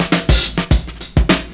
DRUMFILL02-R.wav